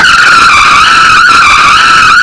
skid2_dry_ex.wav